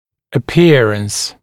[ə’pɪərəns] [э’пиэрэнс] внешность, внешний вид, наружность, признак, проявление